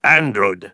synthetic-wakewords
ovos-tts-plugin-deepponies_Spy_en.wav